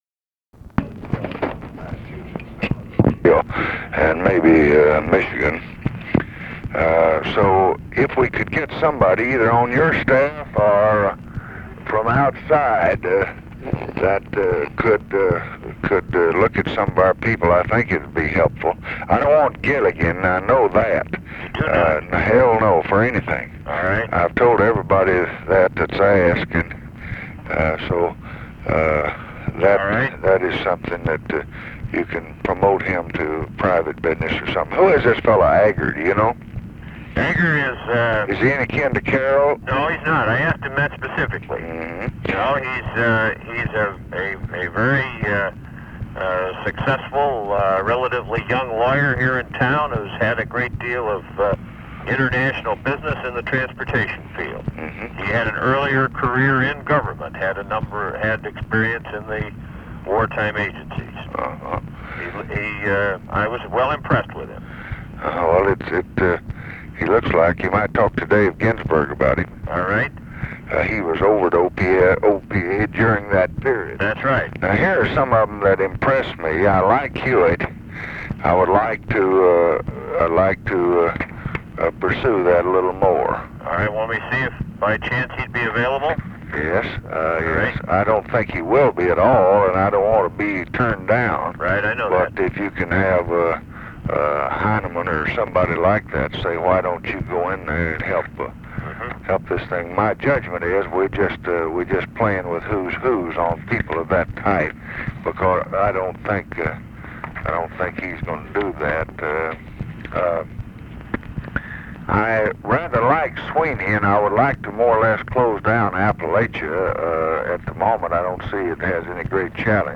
Conversation with JOHN MACY and OFFICE CONVERSATION, December 29, 1966
Secret White House Tapes